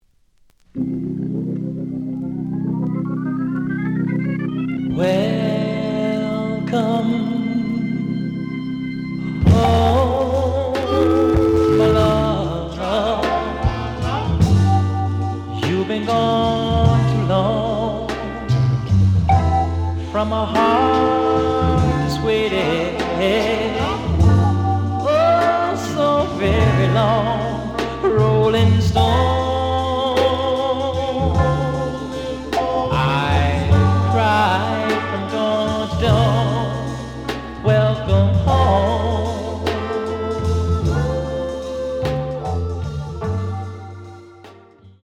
The audio sample is recorded from the actual item.
●Genre: Soul, 70's Soul
Looks good, but slight noise on parts of both sides.